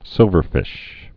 (sĭlvər-fĭsh)